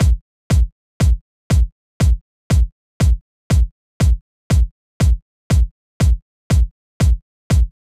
17 Kick.wav